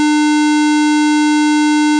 ALR56_Failure.ogg